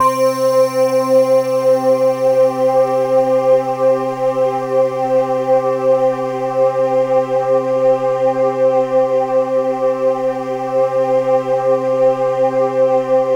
Index of /90_sSampleCDs/USB Soundscan vol.13 - Ethereal Atmosphere [AKAI] 1CD/Partition C/03-CHIME PAD
CHIMEPADC4-L.wav